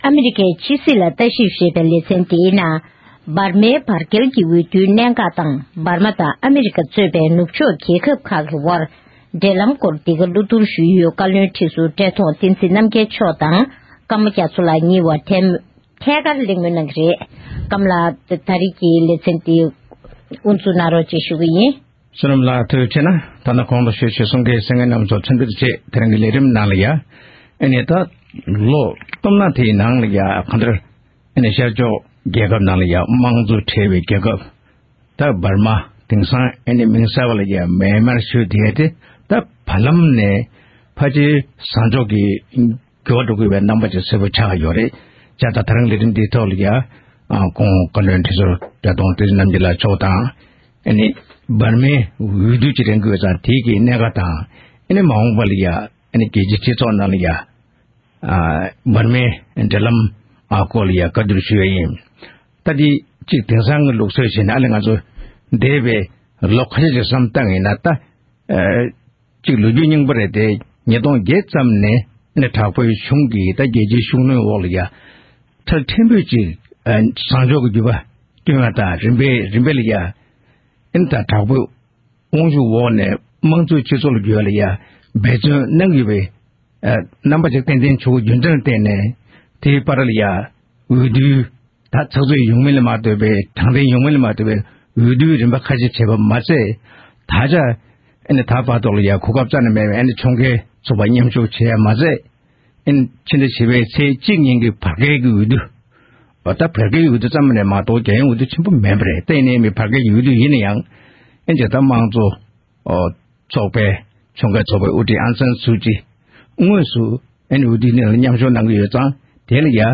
གླེང་མོལ་